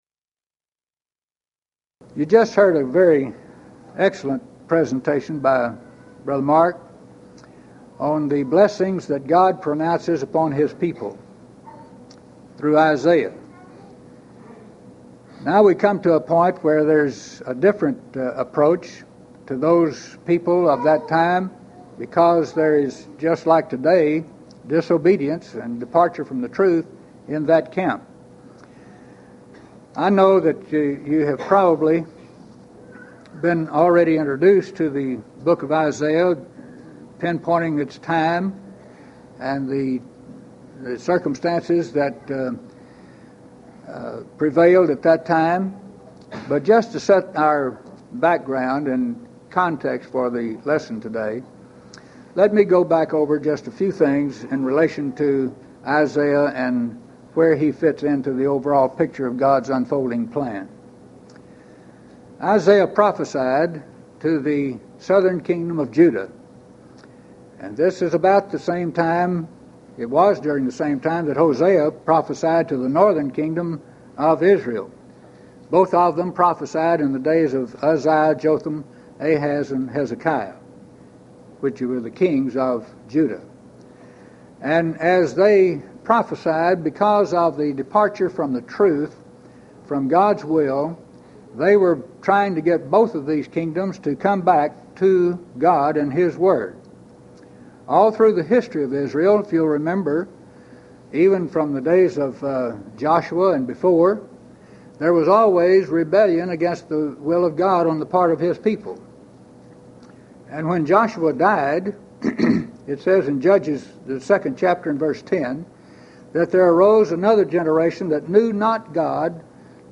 Series: Houston College of the Bible Lectures Event: 1996 HCB Lectures
lecture